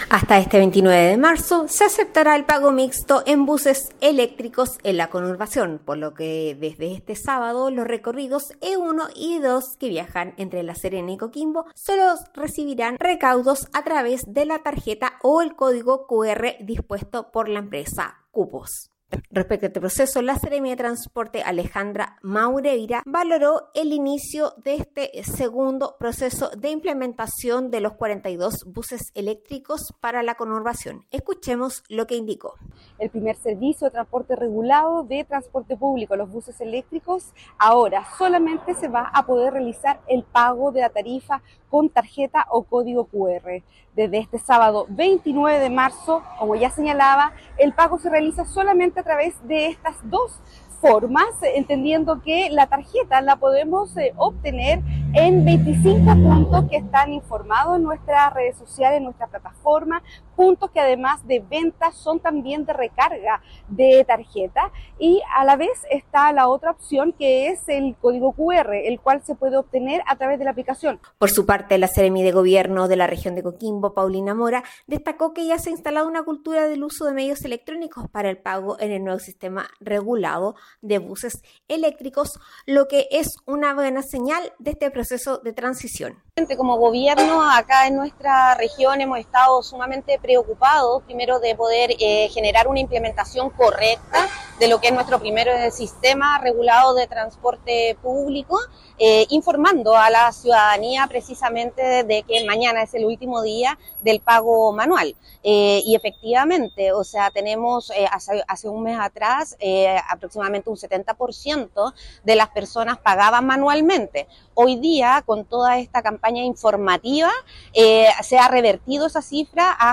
Despacho-Radial-Recaudo-buses-electricos-online-audio-converter.com_.mp3